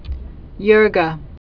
(jûrgə)